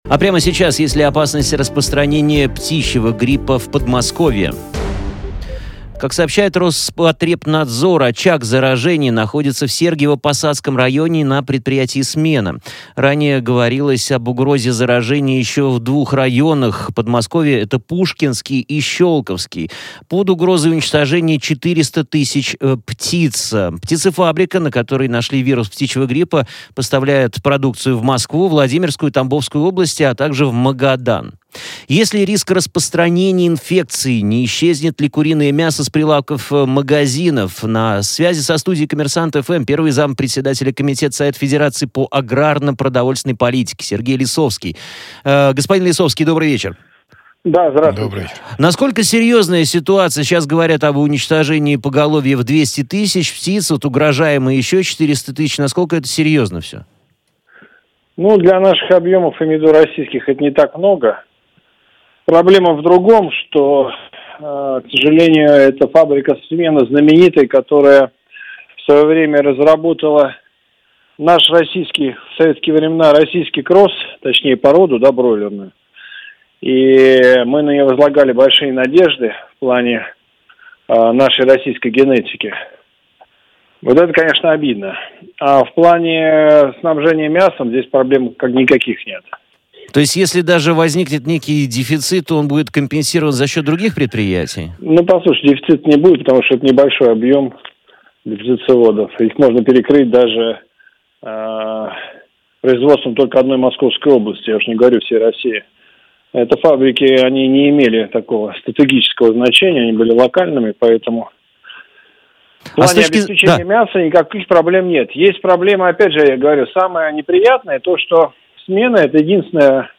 в том числе обсудили ситуацию в прямом эфире с первым заместителем председателя комитета Совета федерации по аграрно-продовольственной политике Сергеем Лисовским.